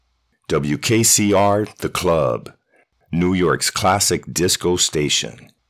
From warm to authoritative, I bring the right tone every time—tailored to connect with your audience.
Radio Imaging